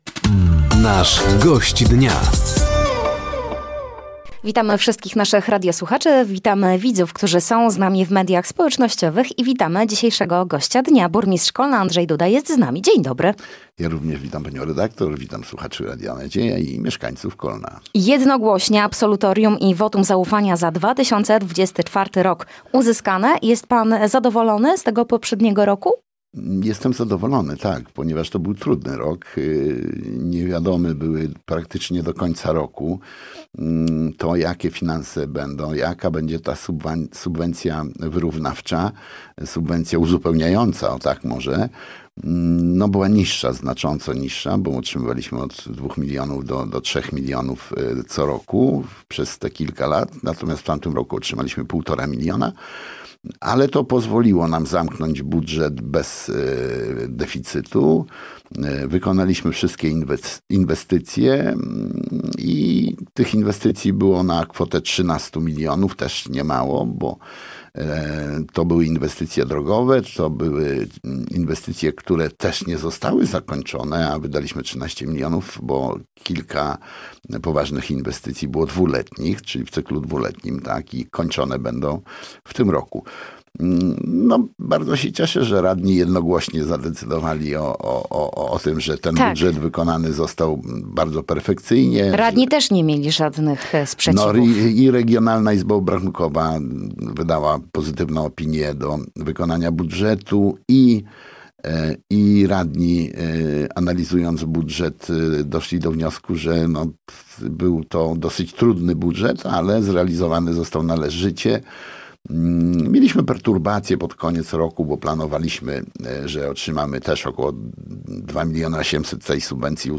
Gościem Dnia Radia Nadzieja był burmistrz Kolna Andrzej Duda. Tematem rozmowy było między innymi absolutorium i wykonanie budżetu w 2024 roku.